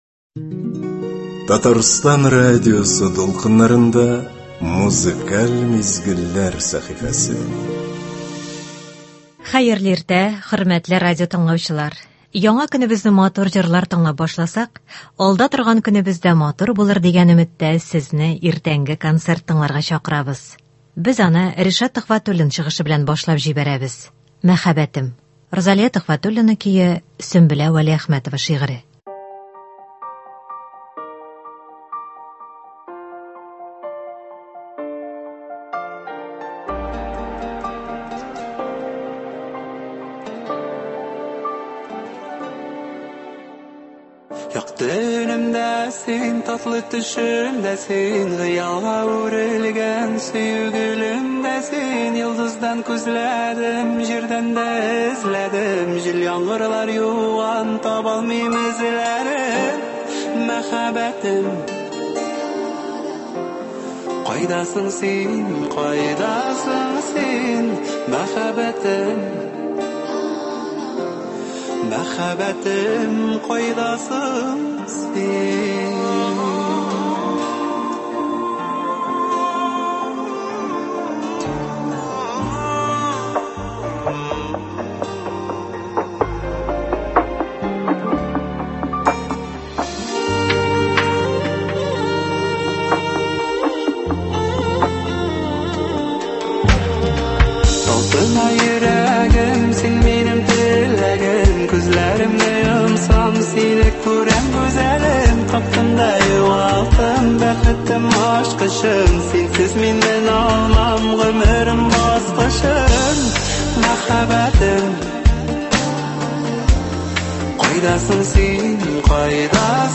Көзге иртәне күтәренке кәеф һәм моңлы җырлар белән башлыйбыз